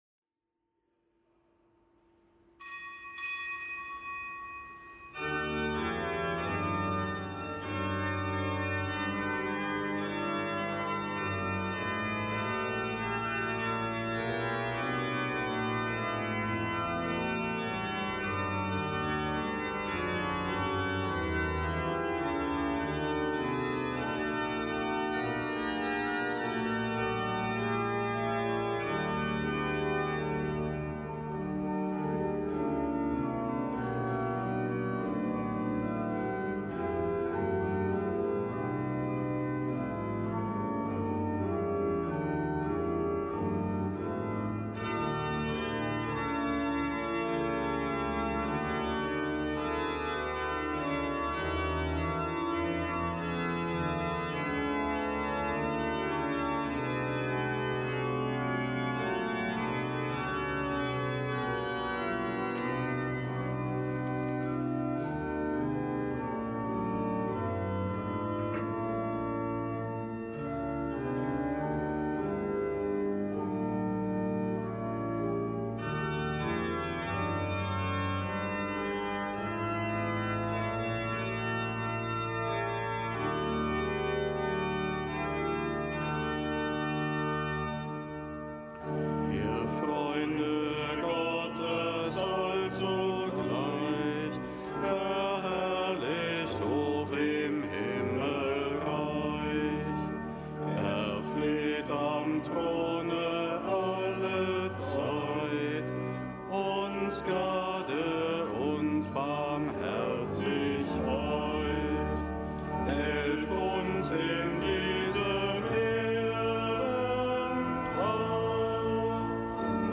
Kapitelsmesse aus dem Kölner Dom am Gedenktag Heiliger Antonius